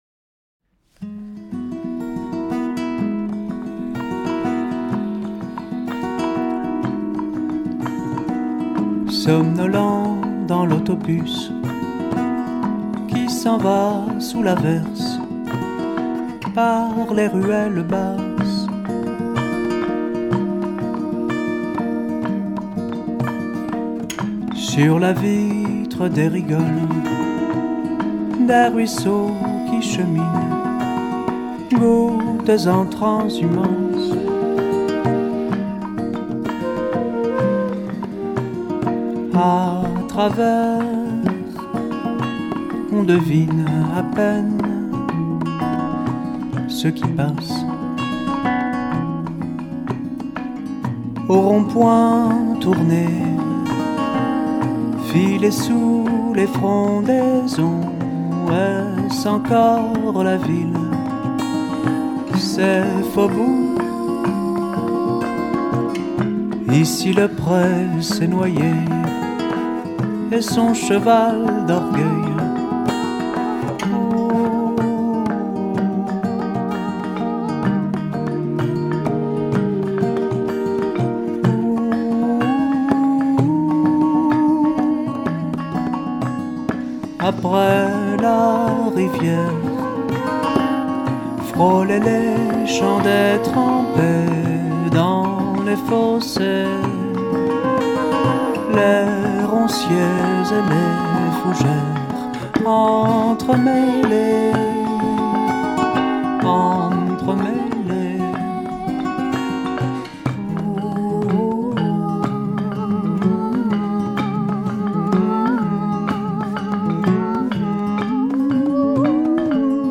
最高！ワールドミュージックのエッセンスを色濃く反映させた、美しくも風変りなフォーク・ミュージック！